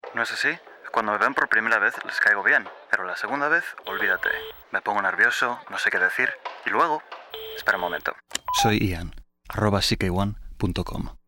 kastilisch
Sprechprobe: Industrie (Muttersprache):
Native Castilian Spanish actor with over 10 years of professional voiceover credits in TV and radio ads, audioguides, corporate films, videogames, eLearning and language courses.